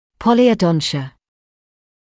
[ˌpɔlɪəuˈdɔntɪə][ˌполиоуˈдонтиэ]полиодонтия, гипердонтия